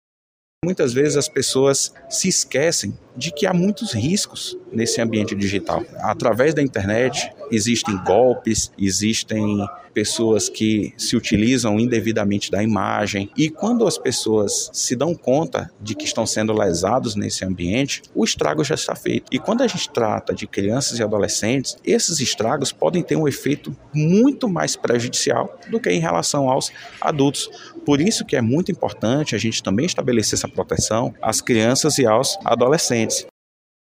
O juiz do Trabalho, André Luiz Marques Cunha Junior, membro do Comitê de Combate ao Trabalho Infantil e de Estímulo à Aprendizagem do Tribunal Regional do Trabalho da 11ª Região (AM/RR) faz um alerta sobre os riscos do ambiente virtual.